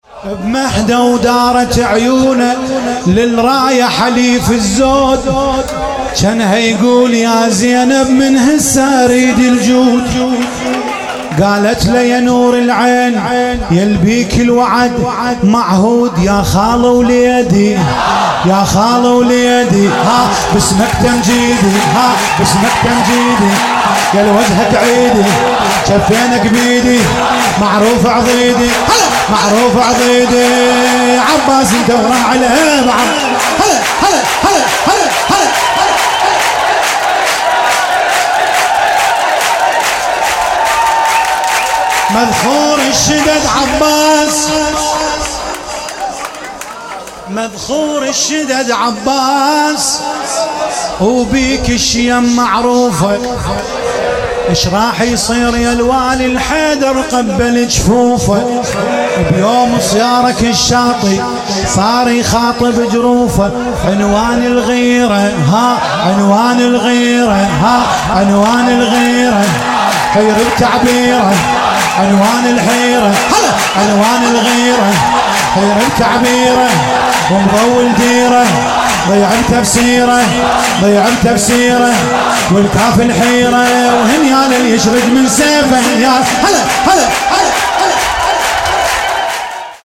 میلاد انوار کربلا